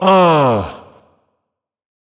SOUTHSIDE_fx_uhh.wav